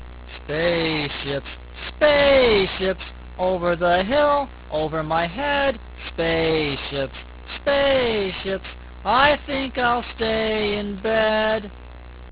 Click this to hear me sing (19K).